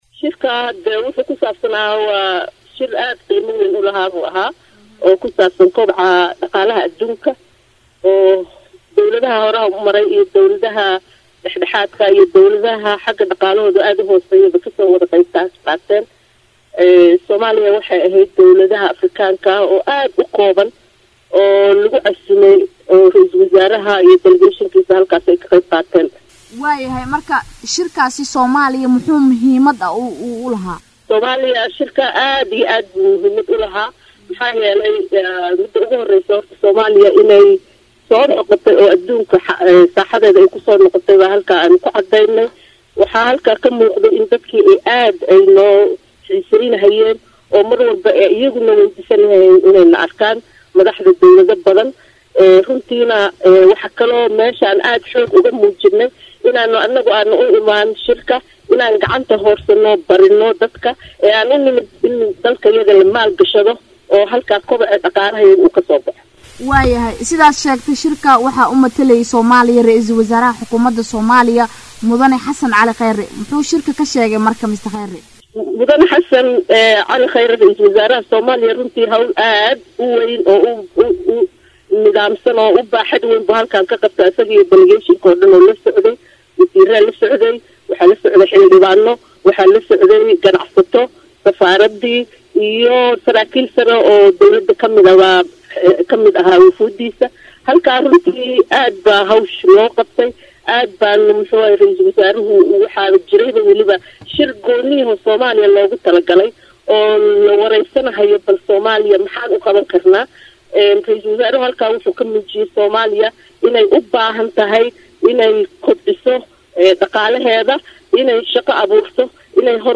Danjiraha Soomaaliya ee Dalka Switzerland Faadumo Cabdullaahi oo u waramaysay Warbaahinta Qaranka ayaa faah faahin ka bixisay waxyaabaha ugu muhiimsan ee shirkaasi ka soo baxay ee quseeya arrimaha Soomaaliya.
Wareysi-Fadumo-Cabdullaahi-insiyaaniyah-safiirka-Somalia-ee-Switzerlan.mp3